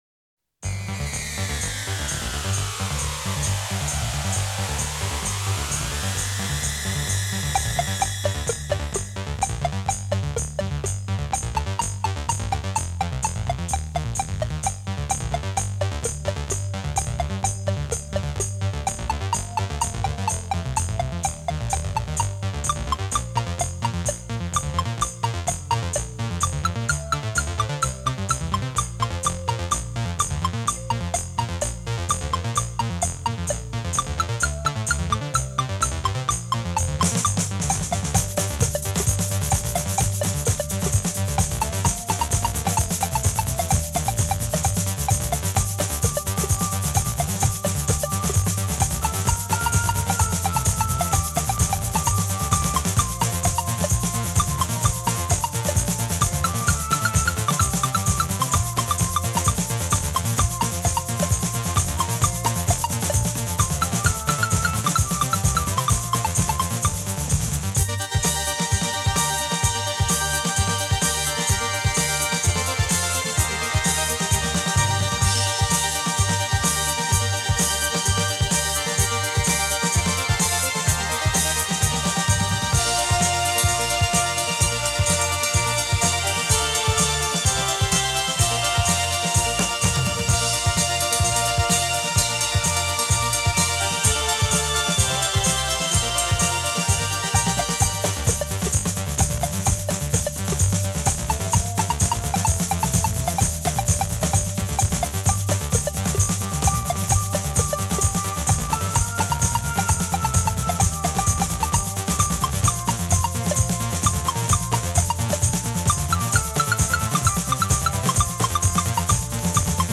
У второго трека больше высоких частот и чуть чуть выше темп.